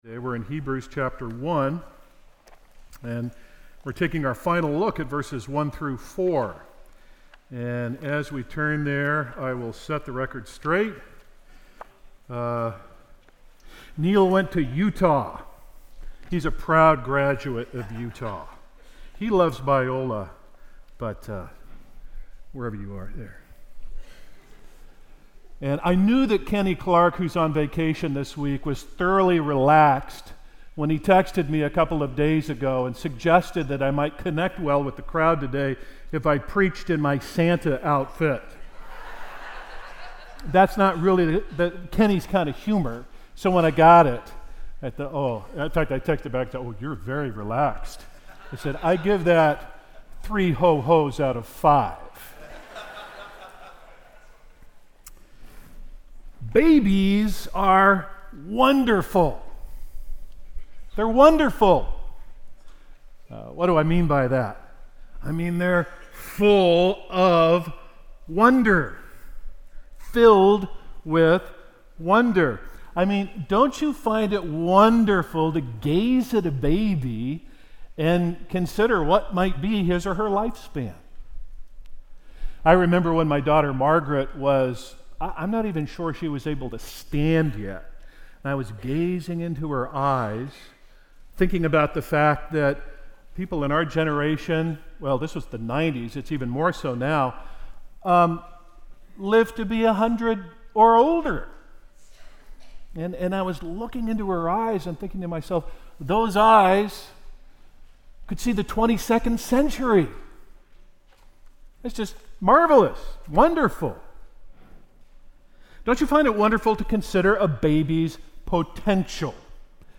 Sermons - Grace Evangelical Free Church